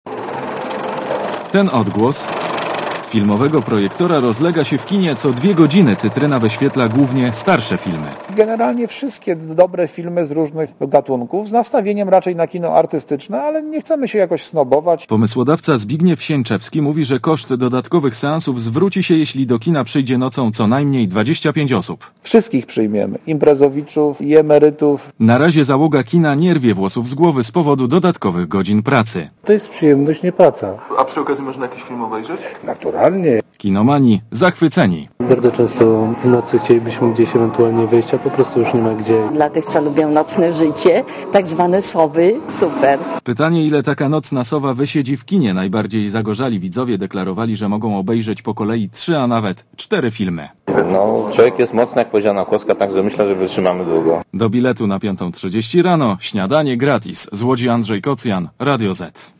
Na pierwszym seansie był reporter Radia Zet (463Kb)